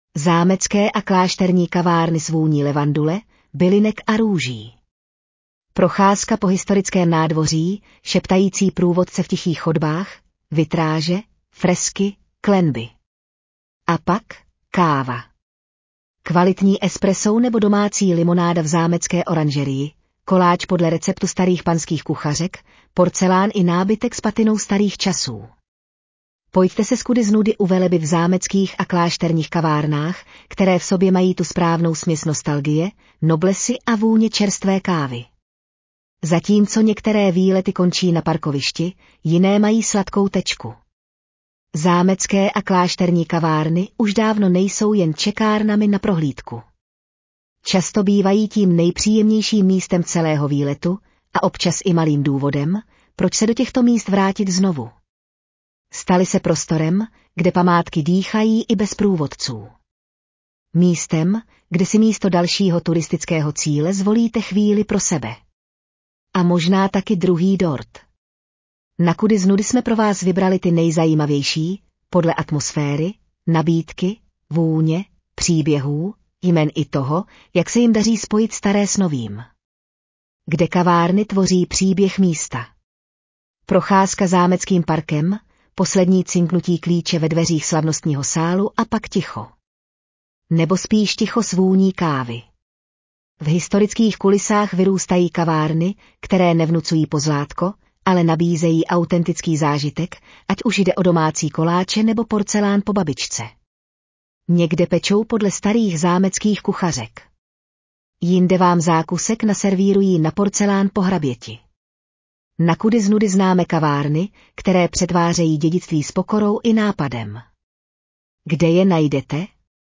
Audio verze článku Zámecké a klášterní kavárny s vůní levandule, bylinek a růží
12720vlastaneural.mp3